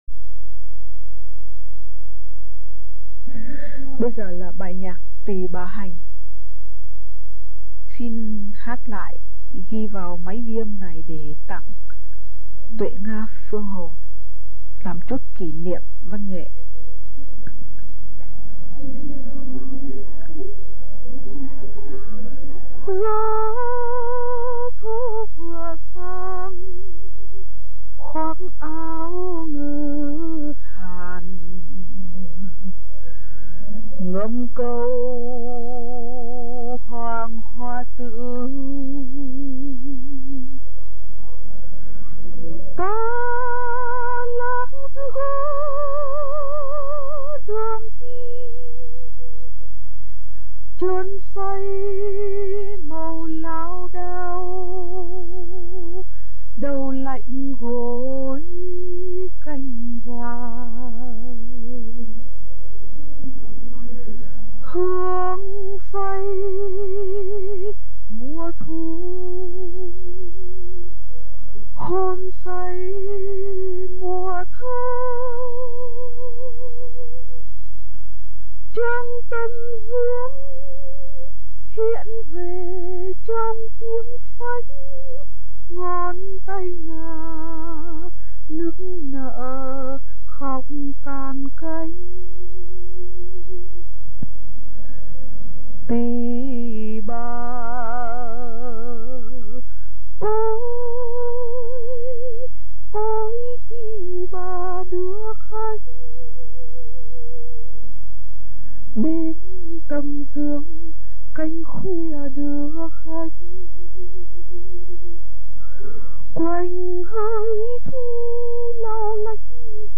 giọng trong trẻo, nhẹ nhàng, thanh thoát.
Những bài trên đây là "xuất khẩu thành ngâm/ xuất khẩu thành ca" , không có nhạc đệm.